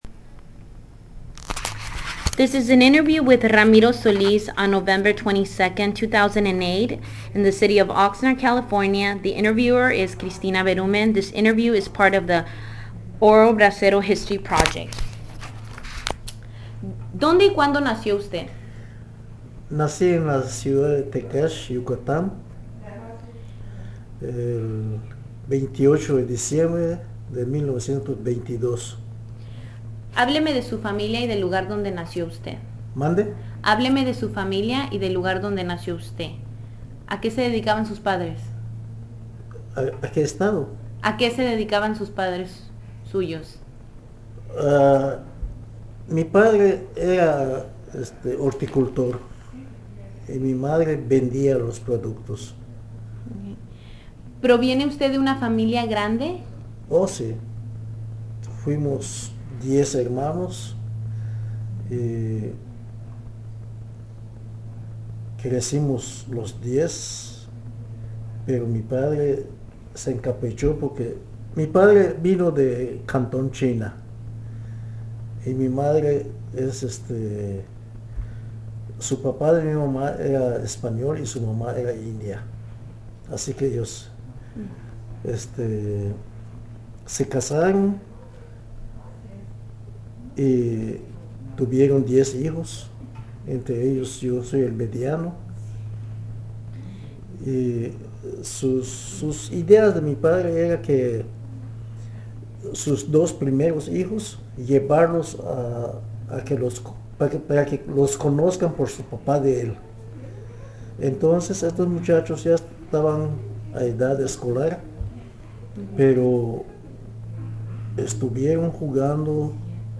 Location Oxnard, California